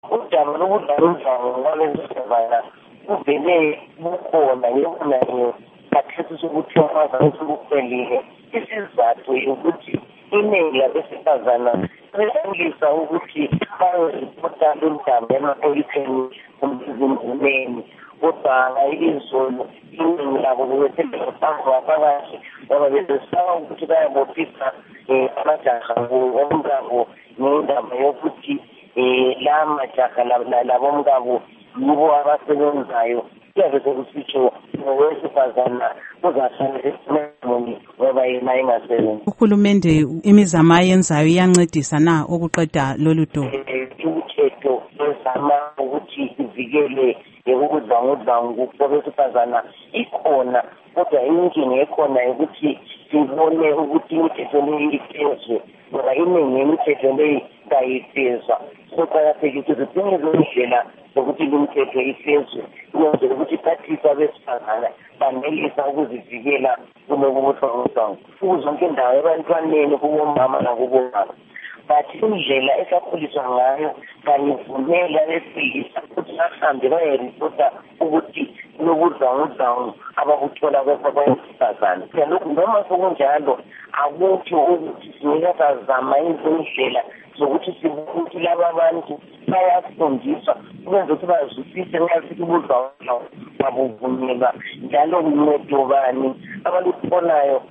Ingxoxo LoNkosikazi Thabitha Khumalo